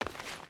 Free Fantasy SFX Pack
Footsteps / Stone
Stone Run 1.wav